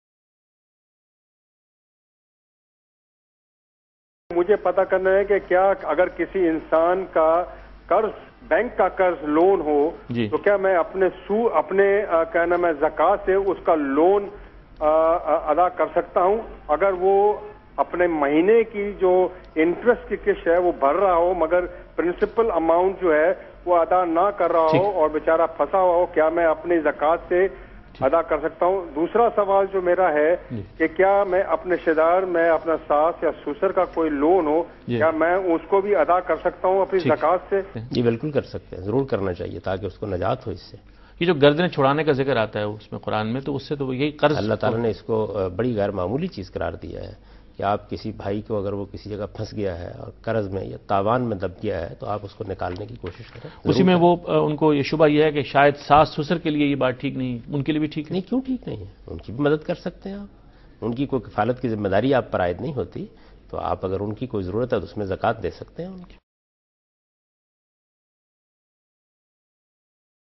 Category: TV Programs / Dunya News / Deen-o-Daanish / Questions_Answers /
Javed Ahmad Ghamidi answers a question regarding "Paying off Debt by Zakah Money" in program Deen o Daanish on Dunya News.